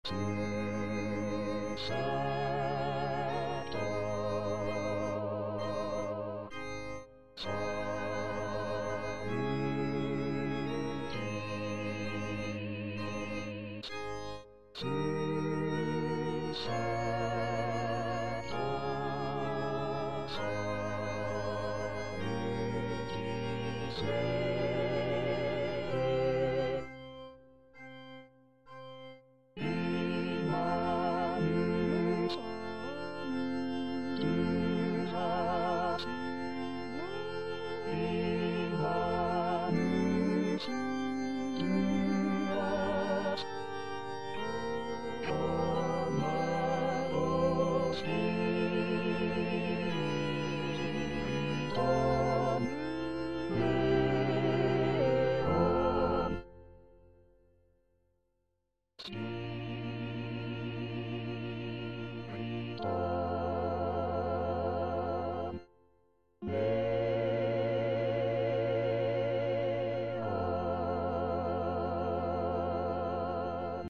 Parole 7: Pater, in manus tuas        Prononciation gallicane (à la française)